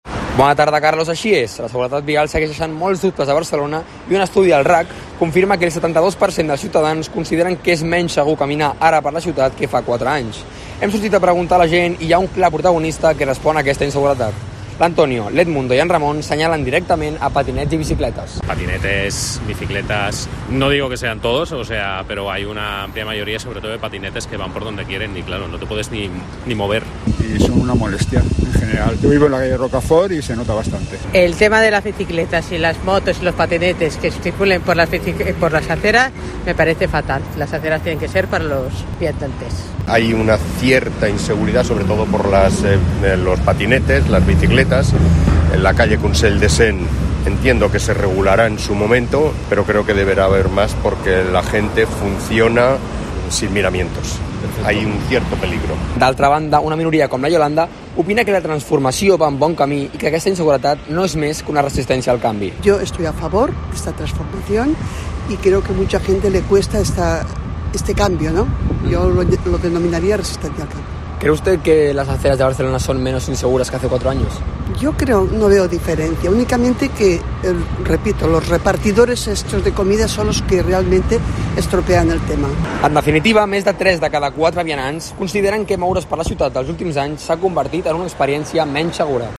crónica sobre la inseguridad vial en Barcelona